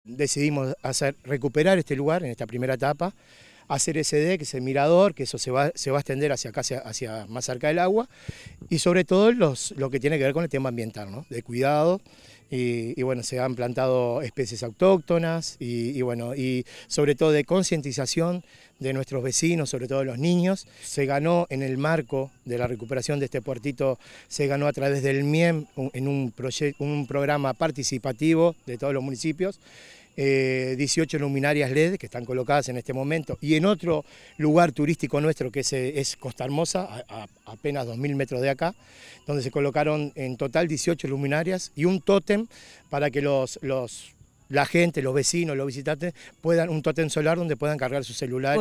Con la presencia de autoridades nacionales, departamentales, locales, vecinas y vecinos, se realizó la inauguración de El Puertito en el Municipio de Aguas Corrientes.